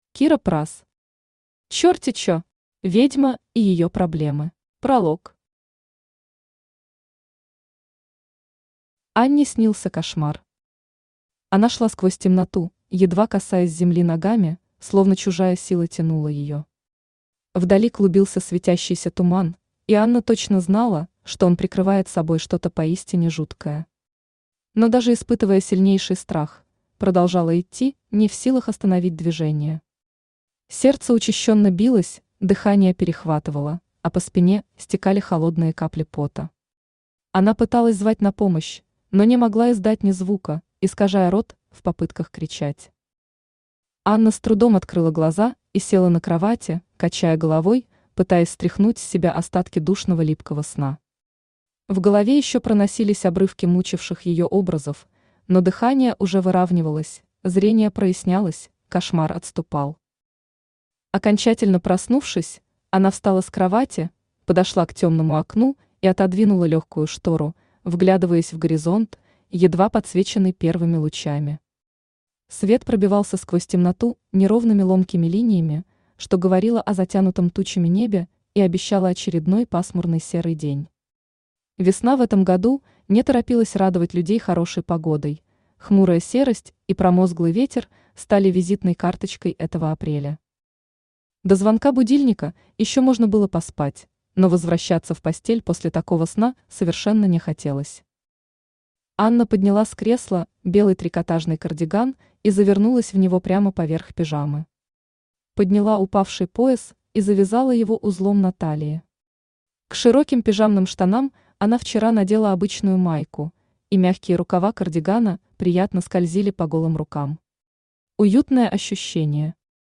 Аудиокнига Чёрти чё! Ведьма и её проблемы | Библиотека аудиокниг
Ведьма и её проблемы Автор Кира Прасс Читает аудиокнигу Авточтец ЛитРес.